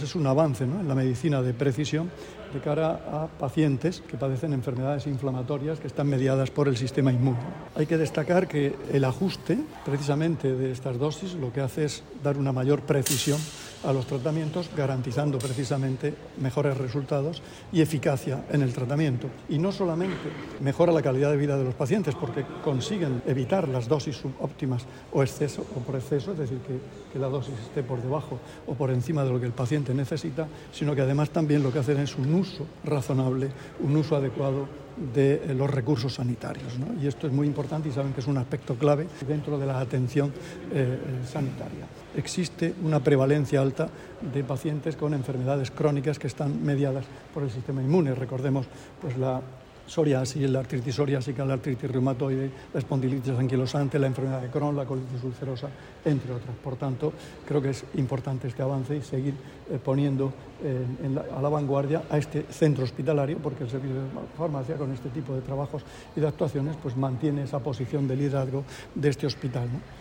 Declaraciones del consejero de Salud, Juan José Pedreño, en su visita al Servicio de Farmacia del hospital Reina Sofía.